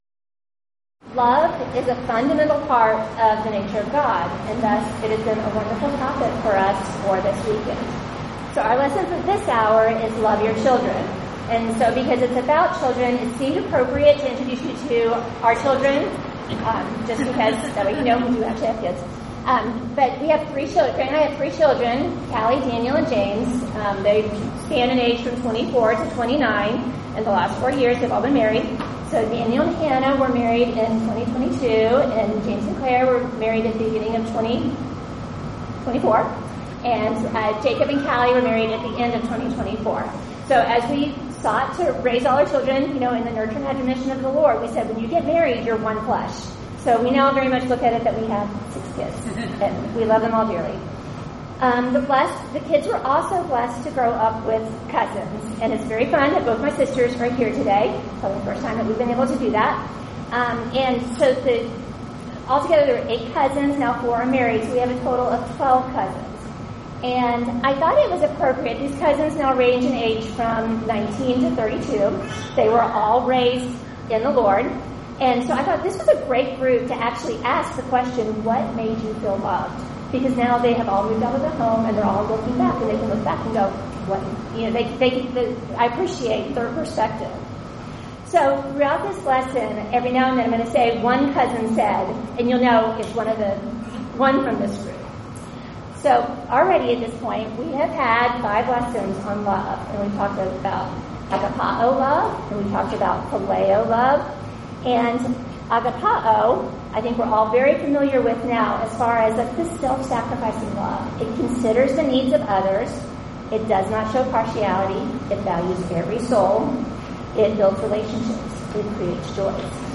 Event: 8th Annual Women of Valor Ladies Retreat
Ladies Sessions